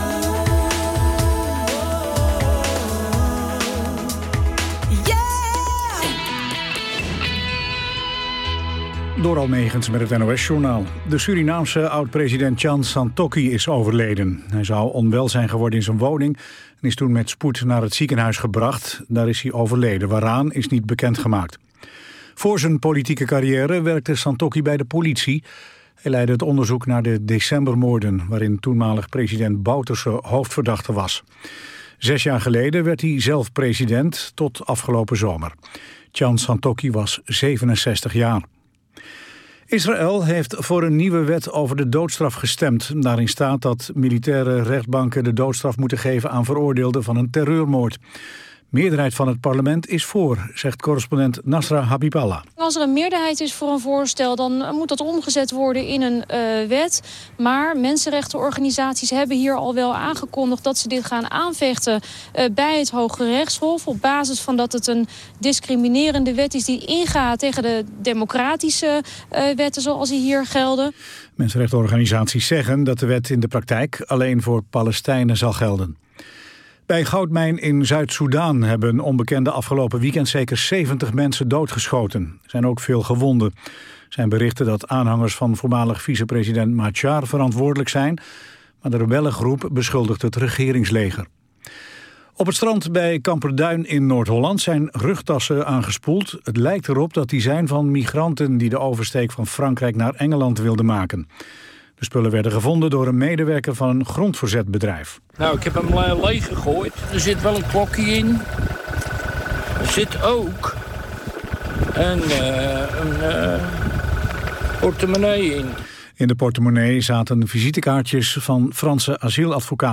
Het word vanavond de laatste studio live-uitzending van het seizoen.
Er wordt jazz gedraaid vanaf de jaren 1920 tot en met vandaag. Het zwaartepunt ligt rond de jaren van de swing, bebop en cooljazz (1930-1960) en fusion (1960-1980), maar ook hedendaagse jazz in alle stijlen komt aan bod; de een meer dan de ander. Er wordt zowel vocale jazz als instrumentale jazz ten gehore gebracht, uit alle windstreken, waarbij de nadruk ligt op de Amerikaanse en Nederlandse jazz.